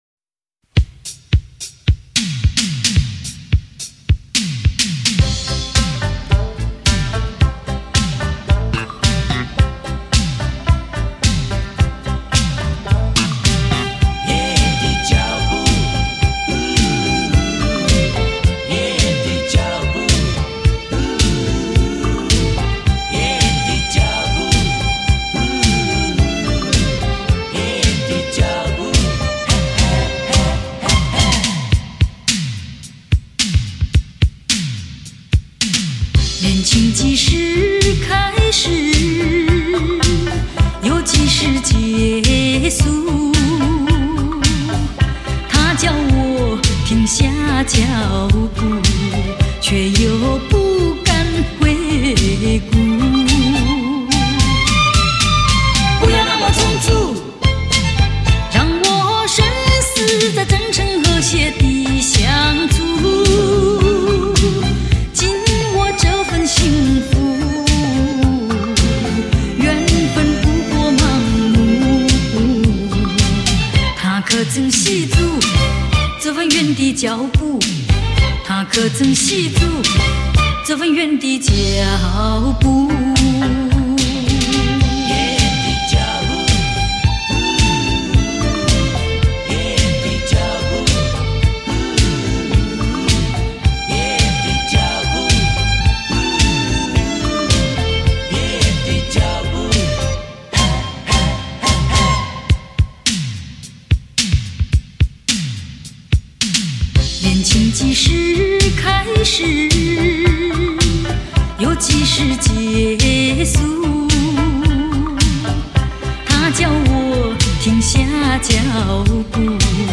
修复珍贵母带，还原那片记忆，歌声中我们走回那个温情的年代。